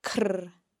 Let’s have a look at how the consonants cn are pronounced in Gaelic.
It is worth noting that the Gaelic sound cn can vary by dialect.